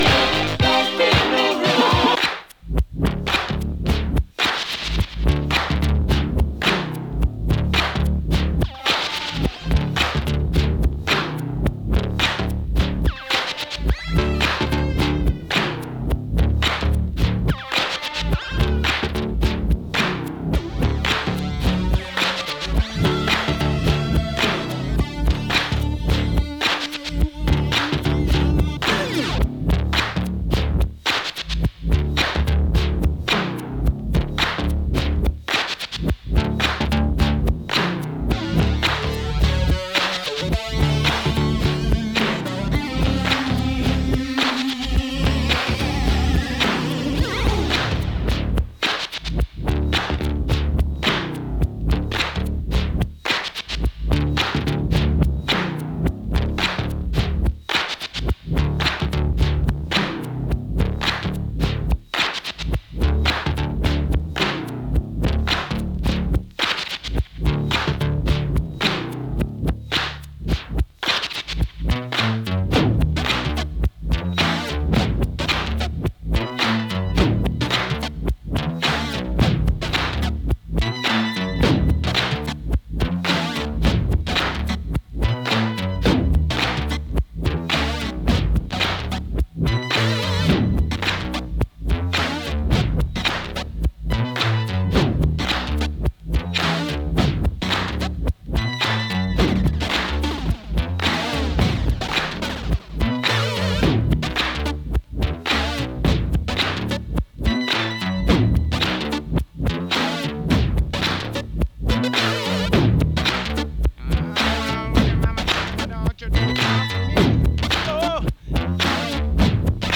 サンプリングねた定番のトボケたP-Funkクラシック！
Instrumental 4.23